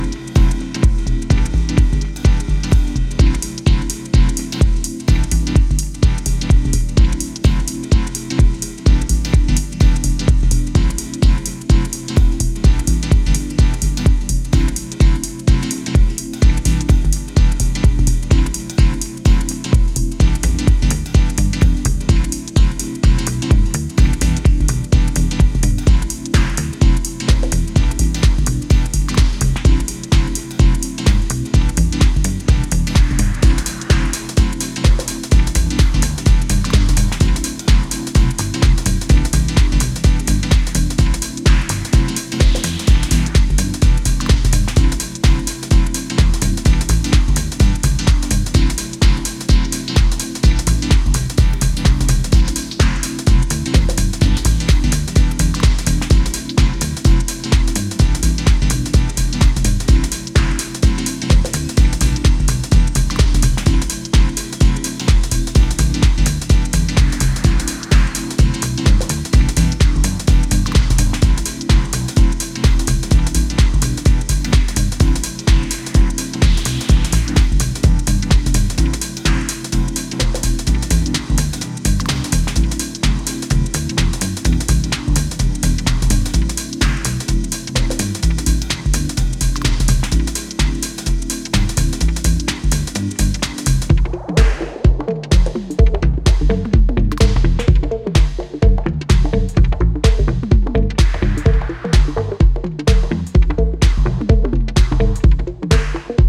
minimal house grooves